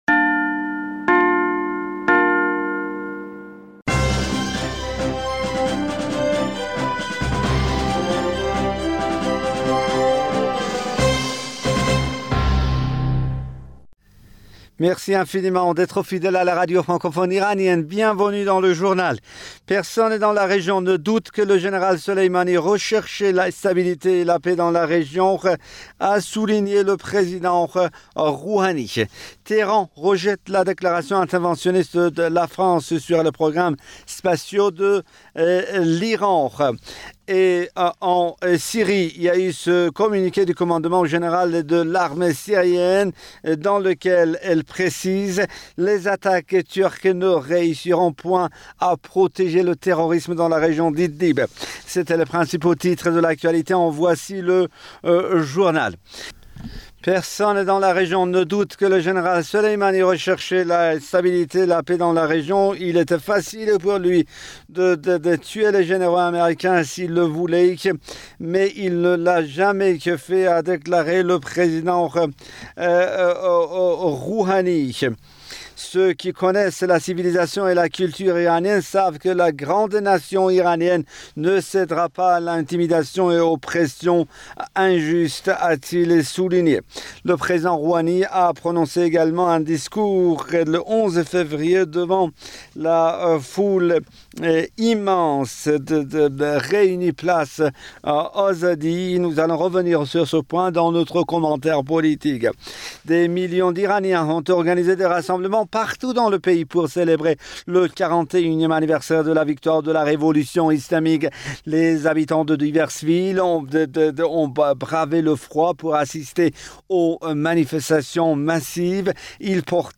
Bulletin d'information du 12 février 2020